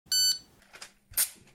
9. Писк об оплате платежного терминала и отрывание чека